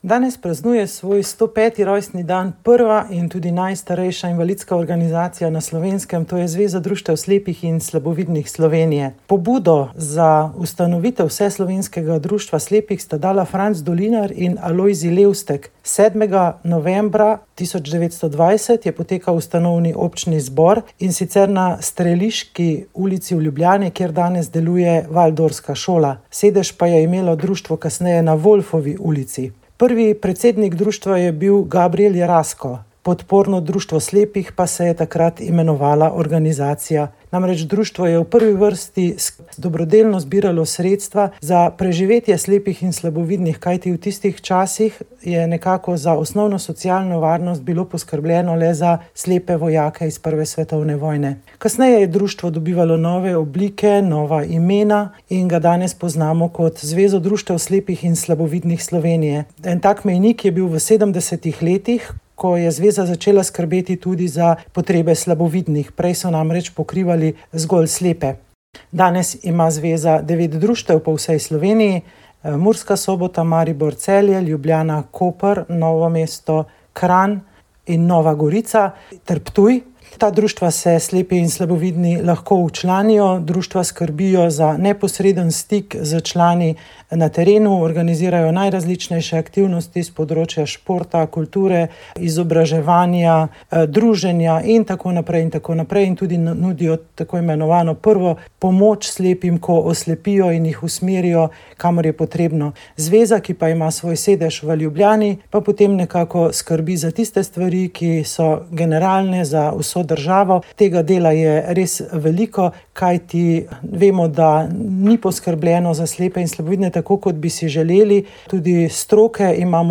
Za naš radio je dogajanje komentiral nekdanji zunanji minister Dimitrij Rupel, v prispevku pa boste slišali tudi, kako Daytonski mirovni sporazum, ki je razdelil Bosno in Hercegovino